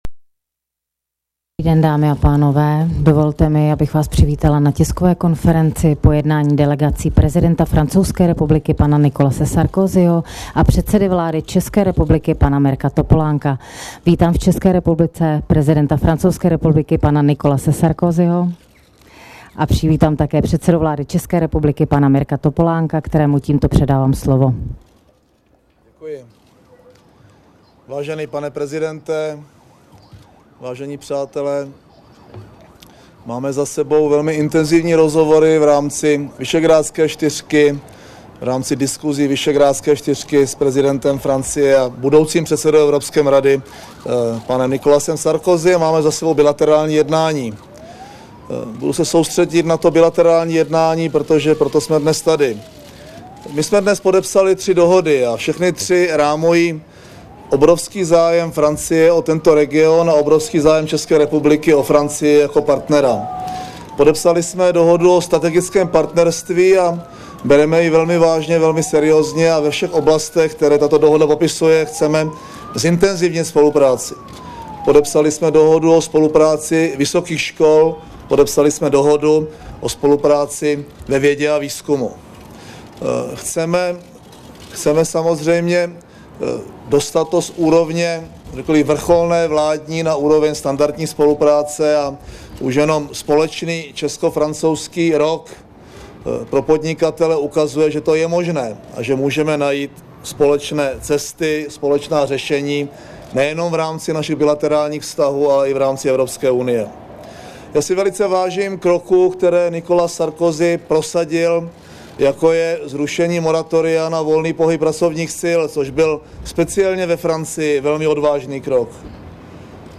Tisková konference po setkání prezidenta Francouzské republiky Nicolase Sarkozyho a českého premiéra Mirka Topolánka v Kramářově vile 16.6.2008